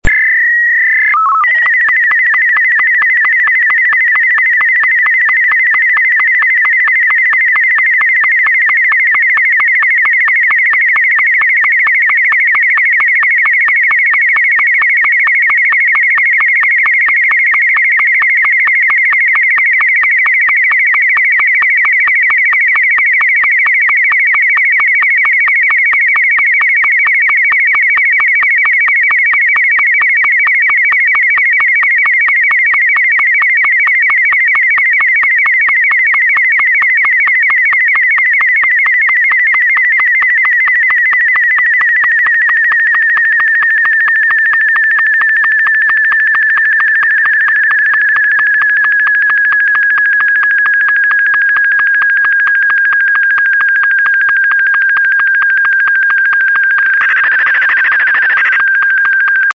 Martin M2